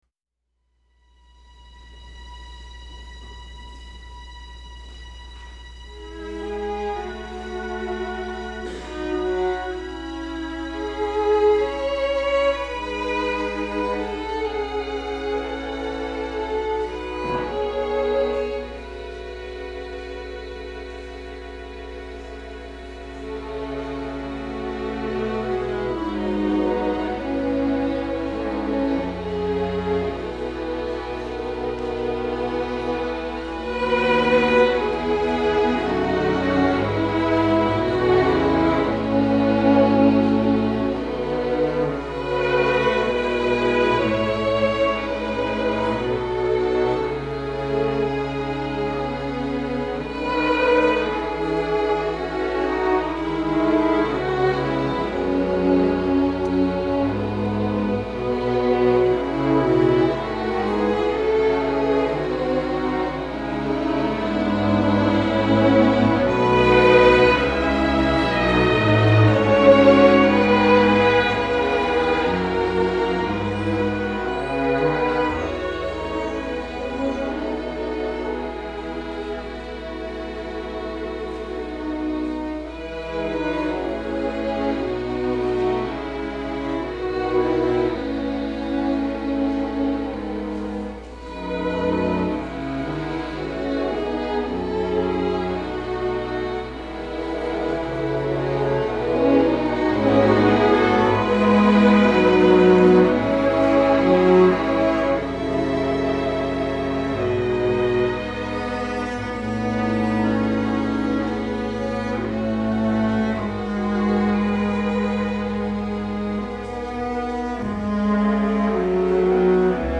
(A piece for any size string orchestra)
A tender and expressive arrangement